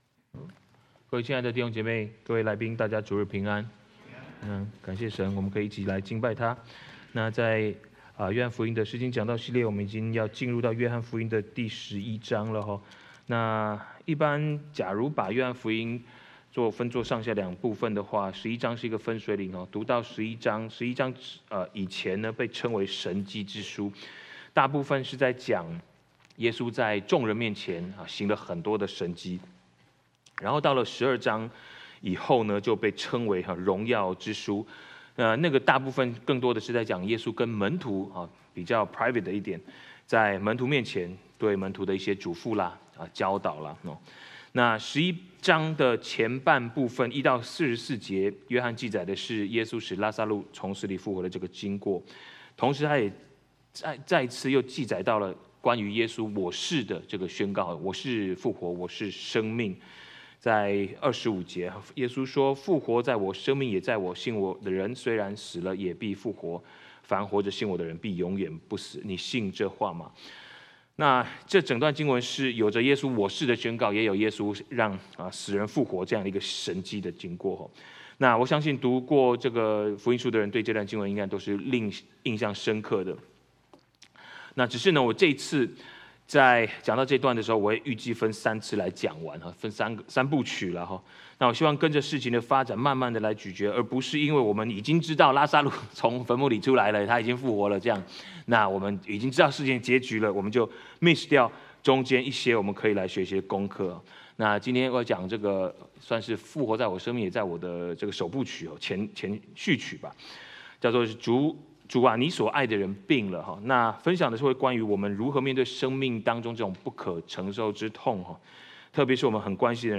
2026 主日證道 | Series | Chinese Baptist Church of West Los Angeles